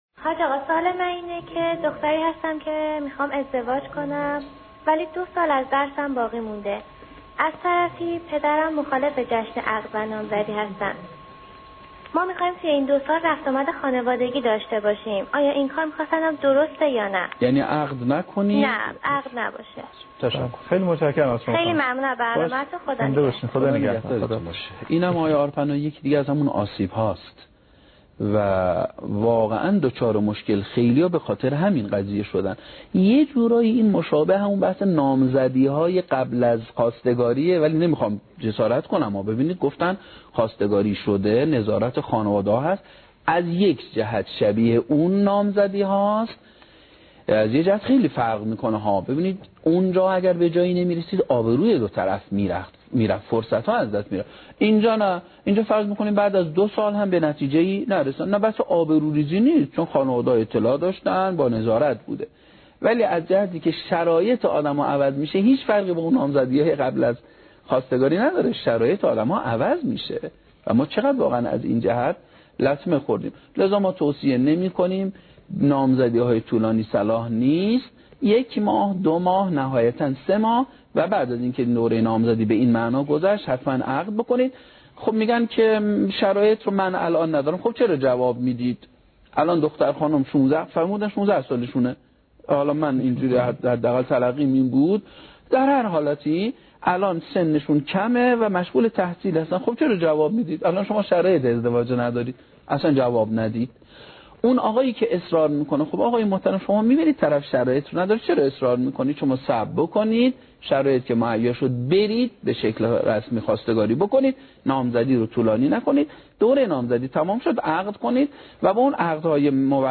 رفت و آمد قبل از ازدواج خطیب